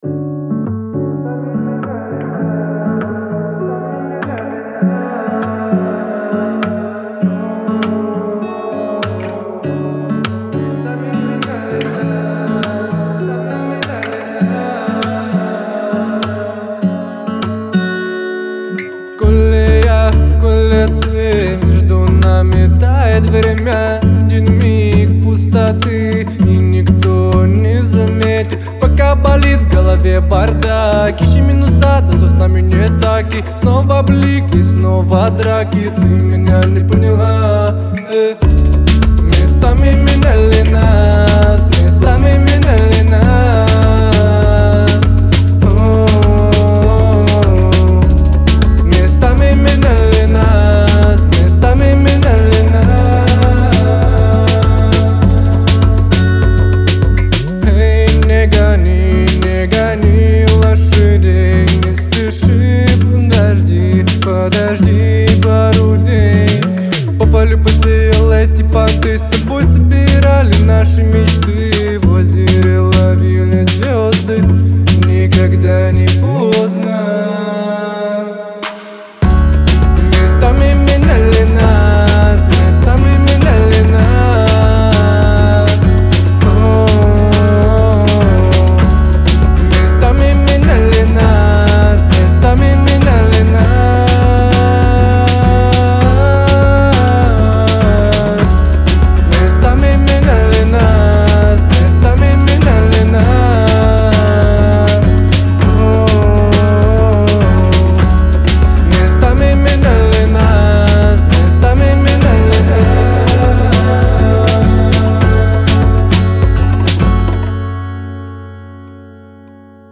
это трек в жанре поп-рок